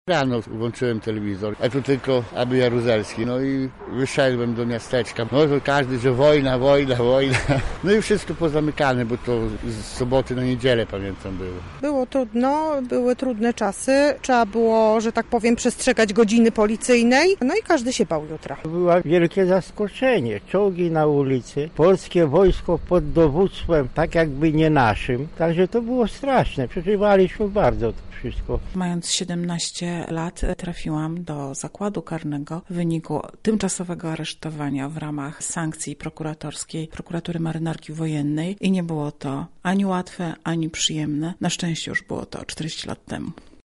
Zapytaliśmy mieszkańców Lublina o wspomnienia związane z tamtym okresem: